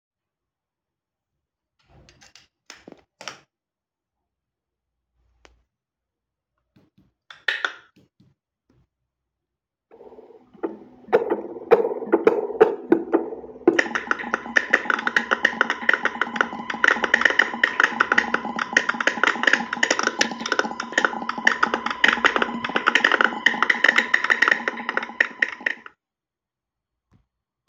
However, now, when powered by it and not by the computer, there’s a lot of noise when playing audio.
in this audio I record a second of sound making sounds with my mouth, then I reproduce them with a granulator (5 grains) and you can hear a lot of noise